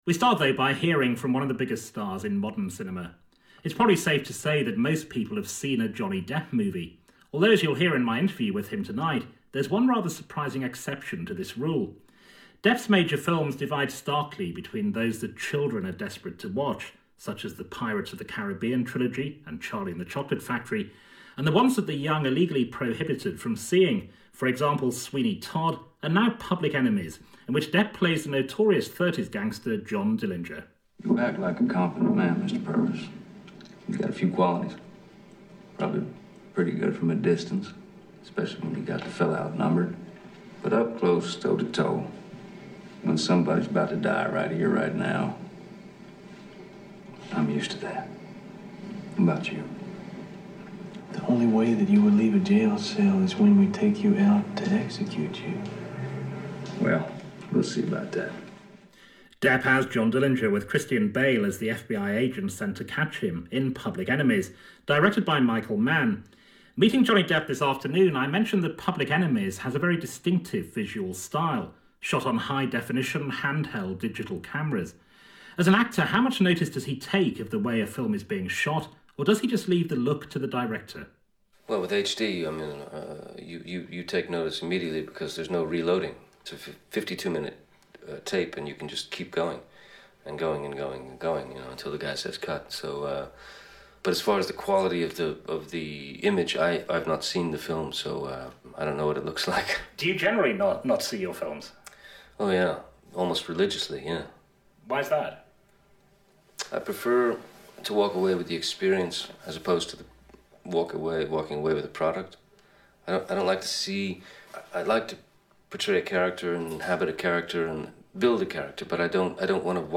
Public Enemies Radio Interview